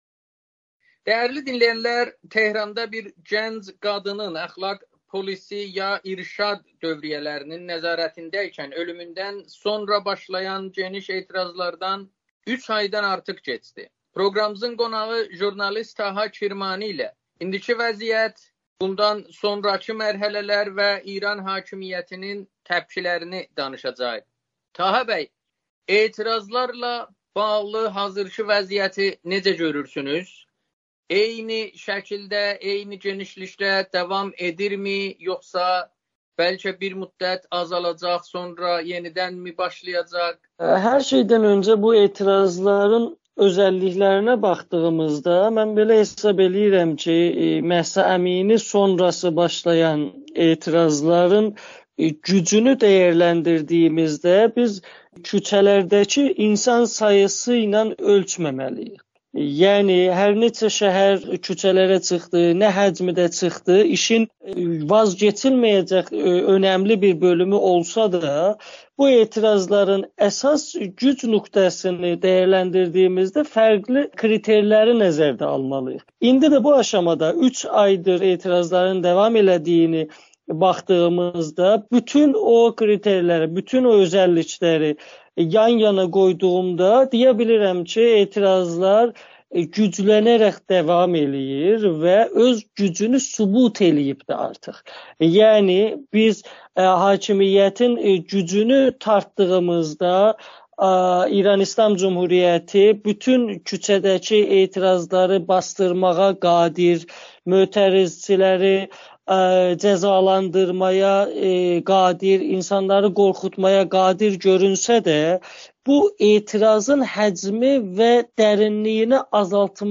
Amerikanın Səsi ilə söhbətdə 3 aydan artıqdır İranda hökümət əleyhinə davam etməkdə olan ümummilli etirazlar ilə bağlı hazırkı vəziyyəti dəyərləndirib. Jurnalist həmçinin İran hökümətinin etirazçıları edam edərək nəyi hədəflədiyini şərh edib.